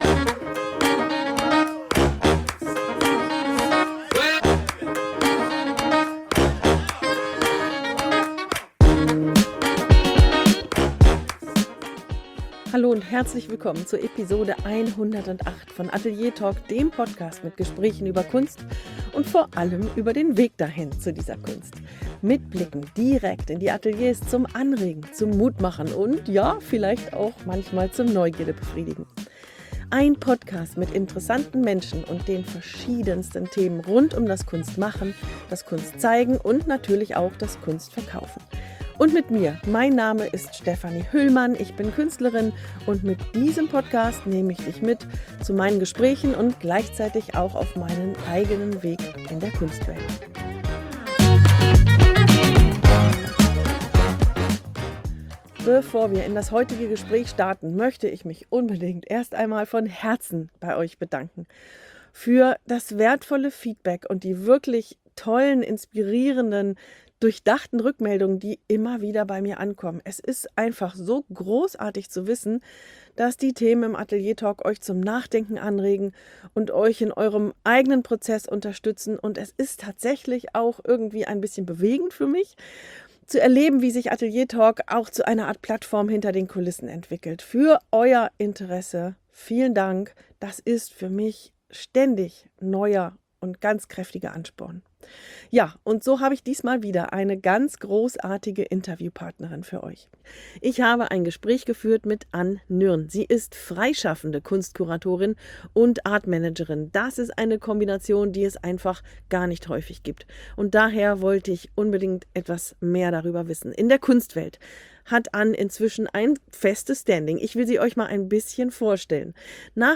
Ein Gespräch über Mut, Ausdauer und die Kraft der Beharrlichkeit